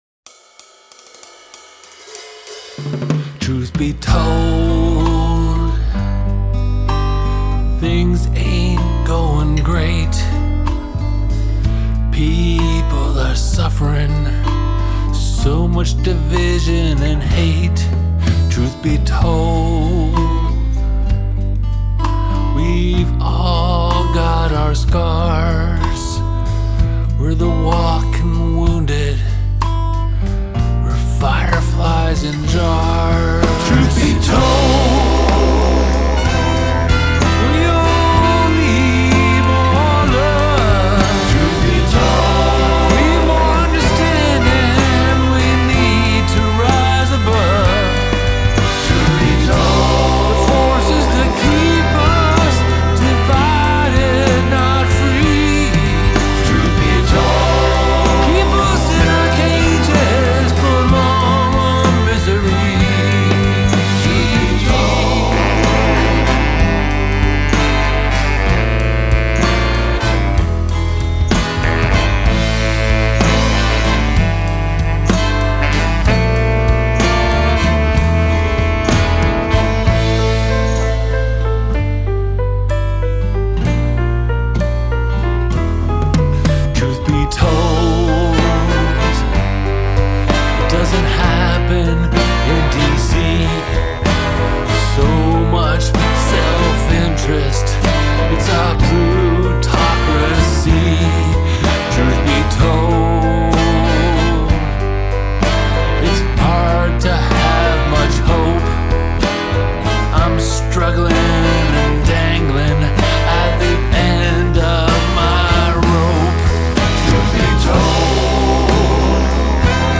vocals, acoustic guitars, piano
drums, percussion
bass
trumpet
trombone
tenor saxophone
baritone saxophone
backing vocals